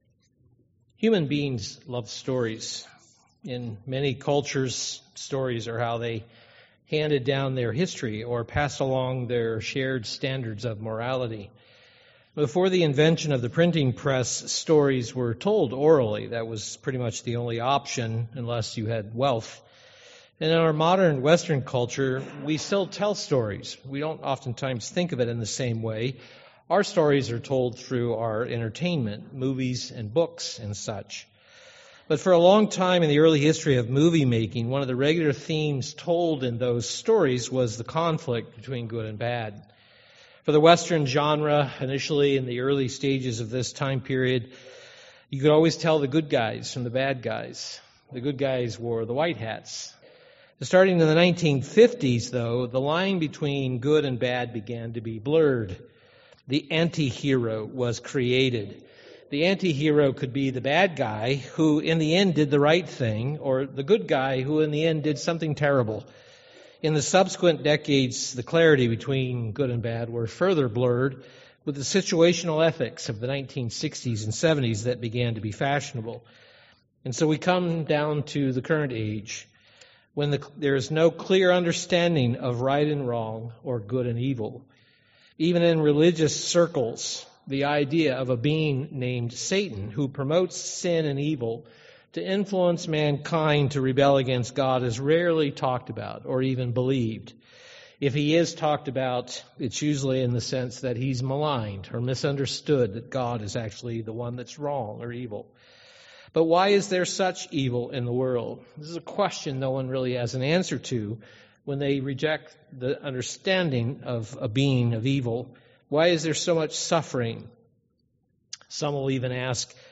This sermon will review the Third Fundamental Belief of the United Church of God. Many don't believe there is a Devil or that he influences mankind to sin and rebel against God.